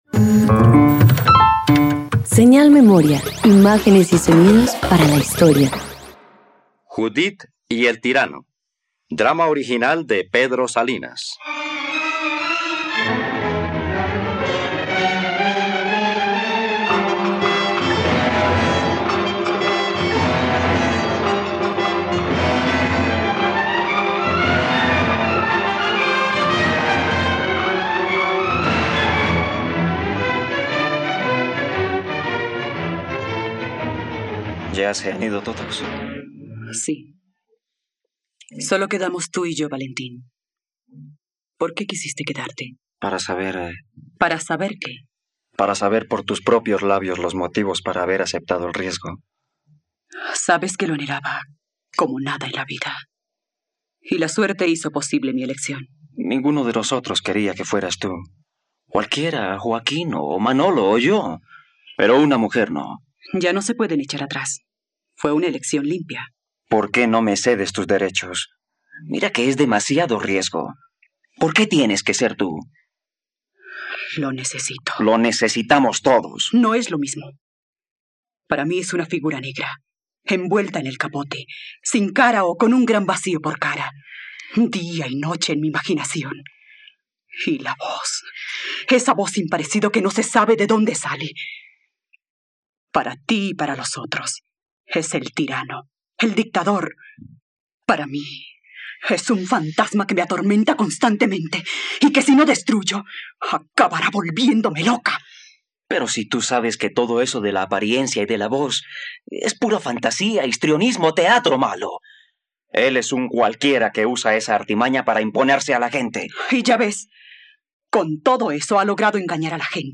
Judith y El Tirano - Radioteatro dominical | RTVCPlay
..Radioteatro. Escucha la adaptación radiofónica de “Judith y El Tirano” de Pedro Salinas, por la plataforma streaming RTVCPlay.